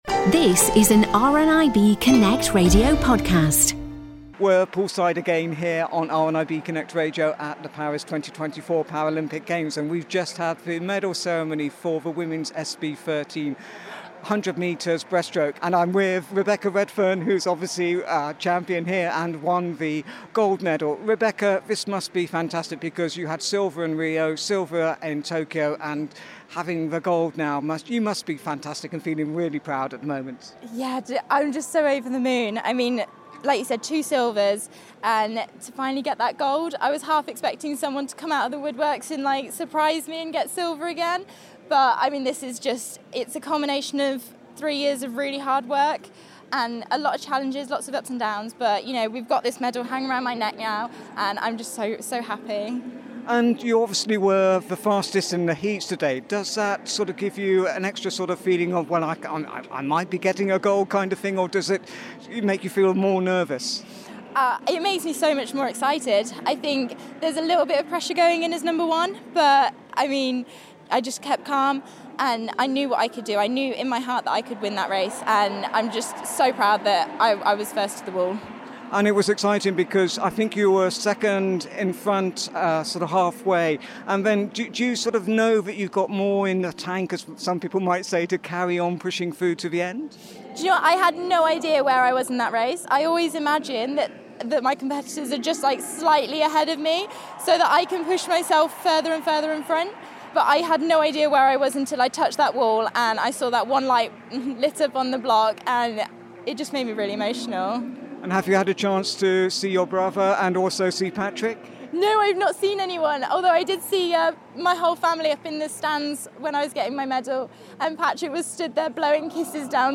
chats with a a very joyful Rebecca Redfern just after she had been presented with her SB13 100 Metres Breaststroke Gold medal.